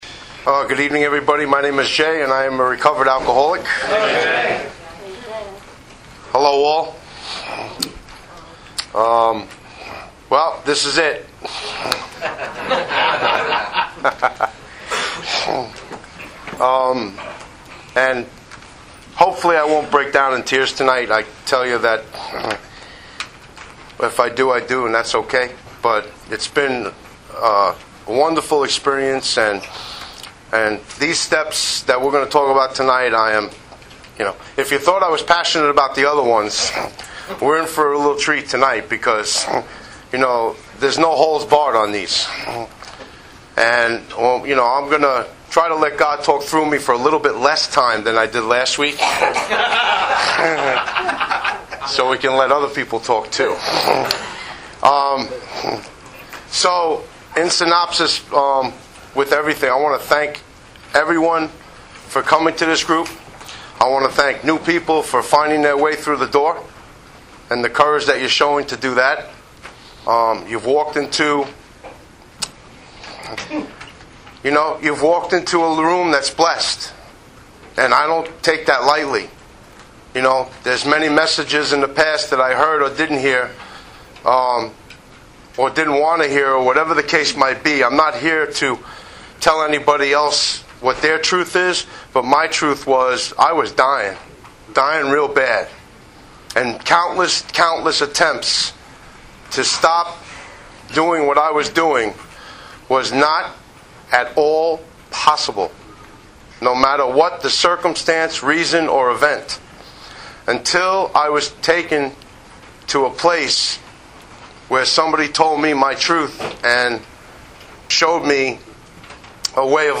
Speaker Tape